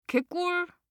알림음 + 벨소리 + 음악감상
알림음 8_개꿀-여자.mp3